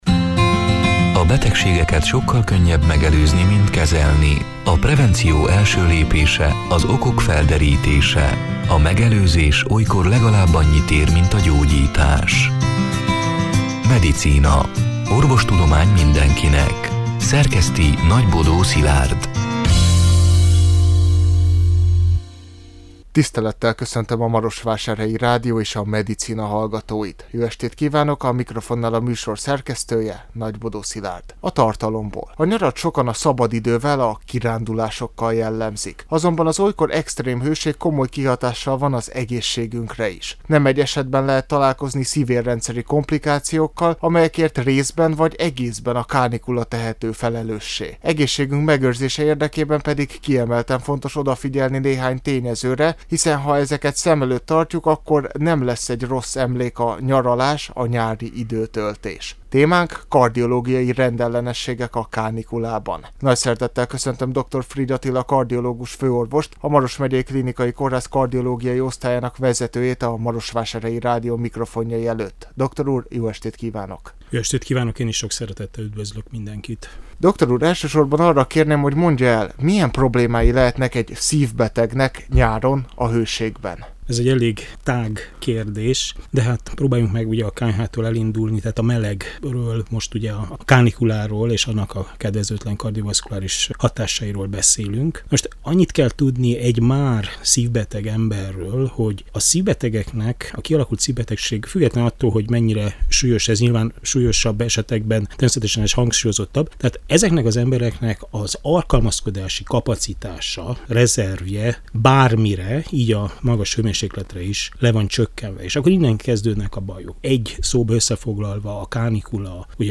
A Marosvásárhelyi Rádió Medicina (elhangzott: 2024. július 24-én, szerdán este nyolc órától) c. műsorának hanganyaga: A nyarat sokan a szabadidővel, a kirándulásokkal jellemzik.